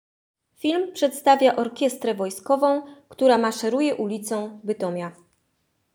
Obchody Święta Niepodległości w Bytomiu
Opis filmu: Orkiestra wojskowa.
Nagranie audio Orkiestra_wojskowa.m4a